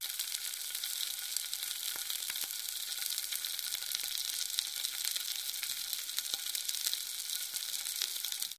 Cooking, Food Boils & Sizzles 2